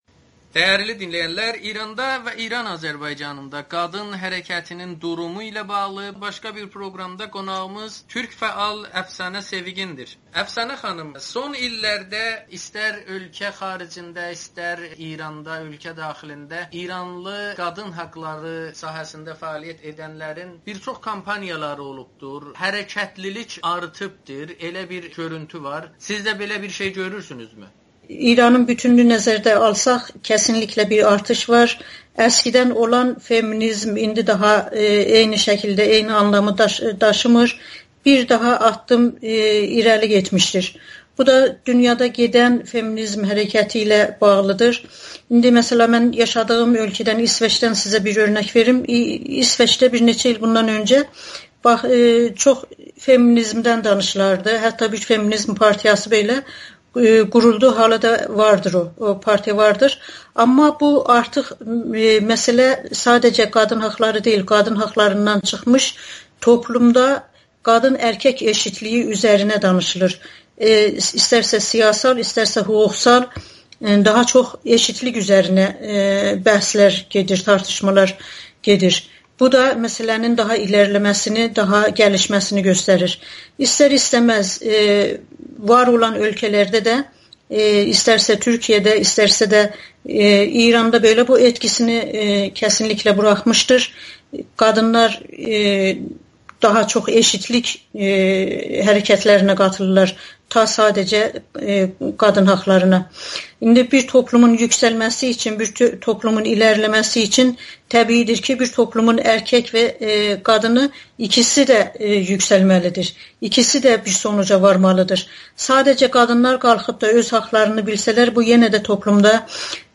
amma geri çəkildilər [Audio-Müsahibə]